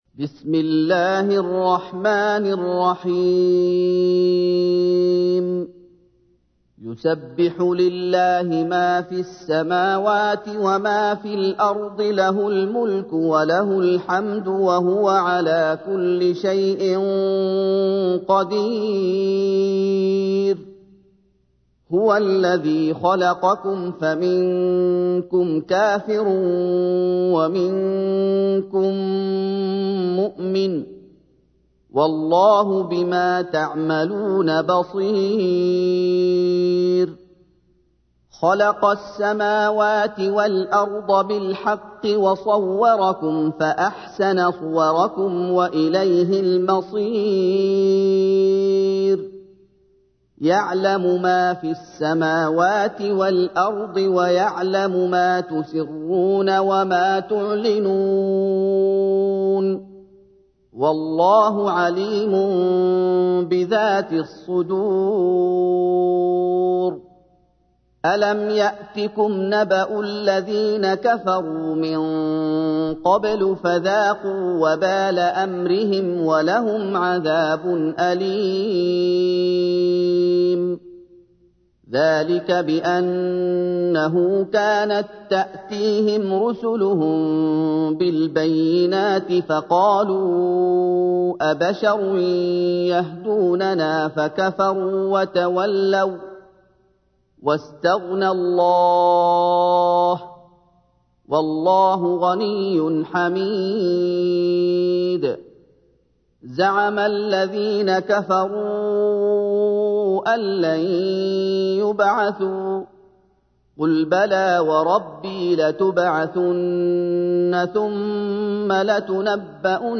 تحميل : 64. سورة التغابن / القارئ محمد أيوب / القرآن الكريم / موقع يا حسين